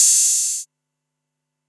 kits/TM88/Crashes & Cymbals/Ride 888 2.wav at main
Ride 888 2.wav